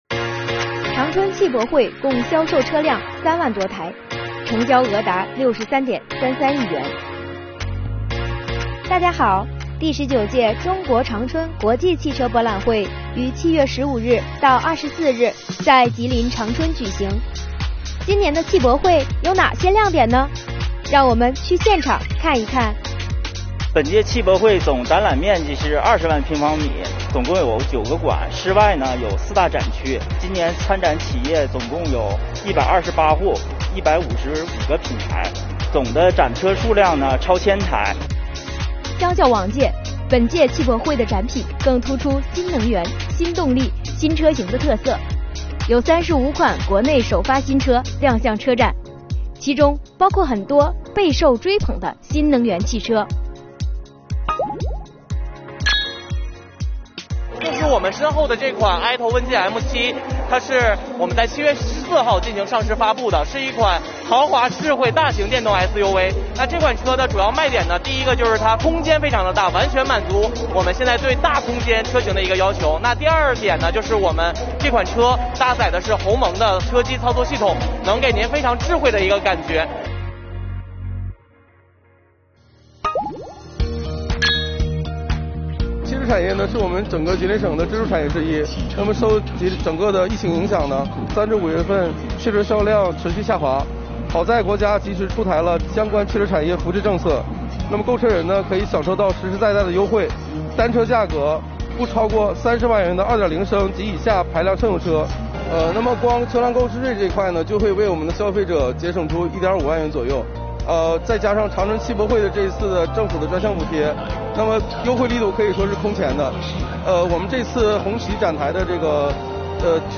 汽博会上有哪些亮点和税元素，让我们跟随记者一起去看一看。